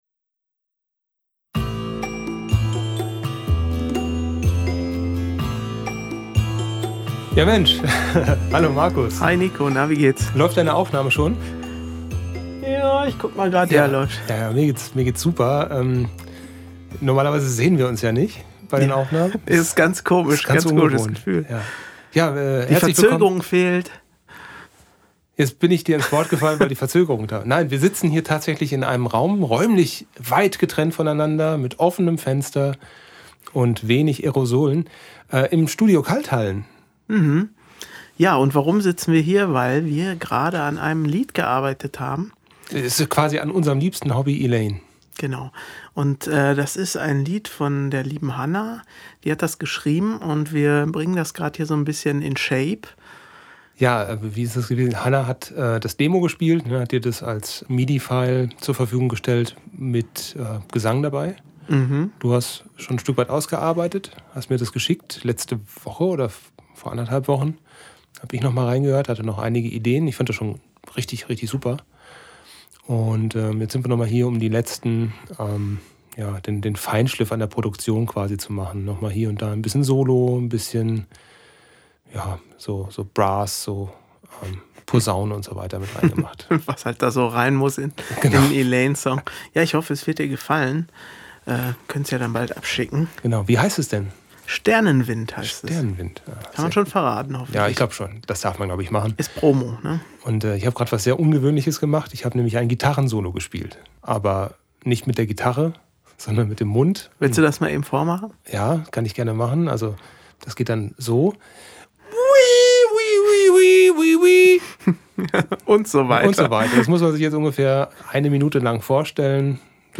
Erstmals ohne viel technology, dafür mit viel Abstand und wenig Ayo-solen.